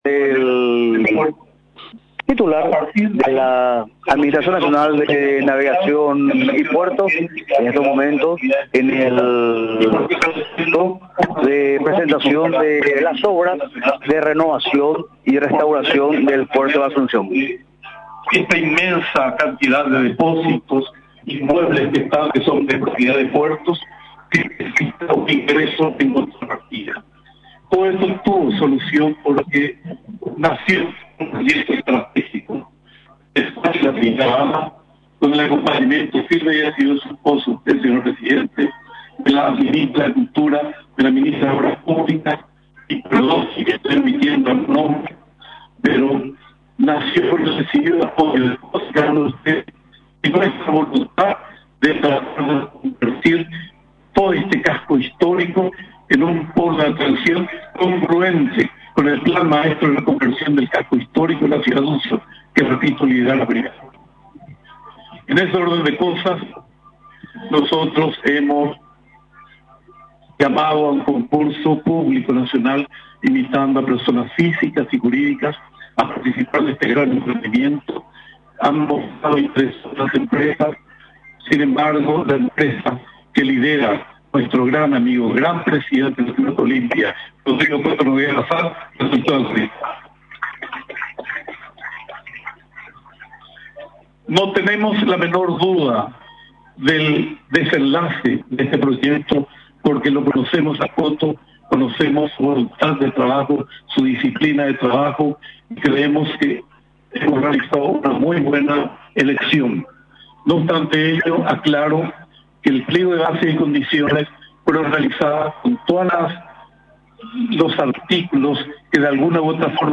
En un acto que contó con la presencia del presidente de la República Santiago Peña y la Primera Dama de la Nación, Leticia Ocampos, se presentó el proyecto de reconversión del Puerto de Asunción, proyecto que tiene por objetivo fusionar el arte, la gastronomía y la cultura en un solo punto, a través de una infraestructura acorde a los tiempos modernos.